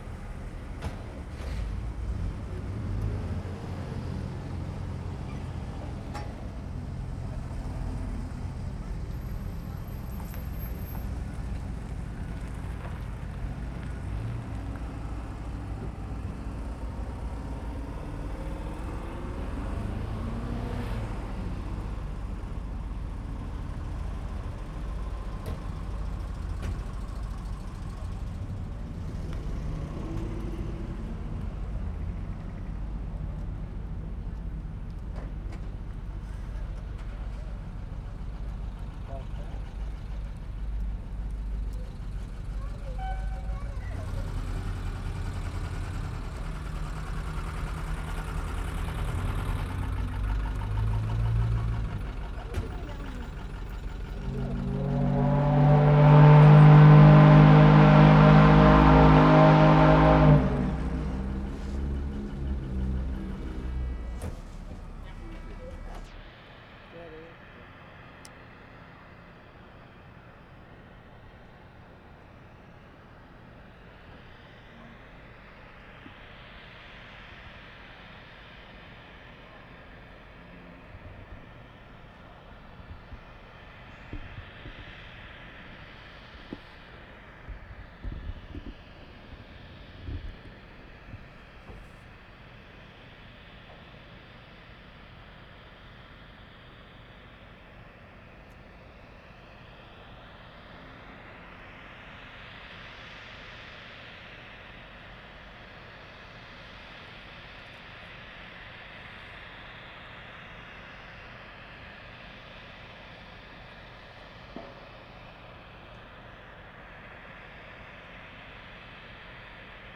Excerpts of longer Chemainus recordings:
Vehicle engines and Mill horn. Very rich sound. (2'20")